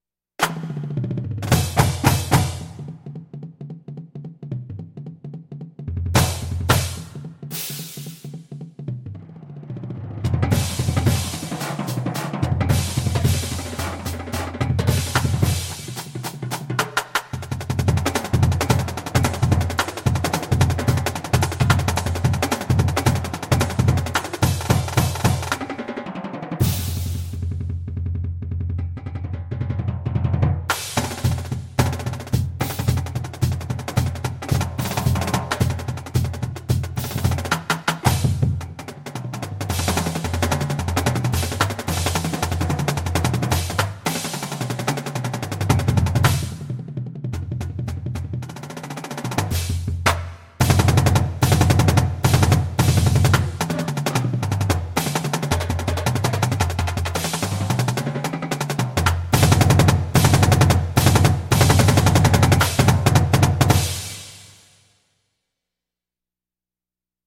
Voicing: Marching Percussion